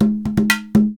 PERC 28.AI.wav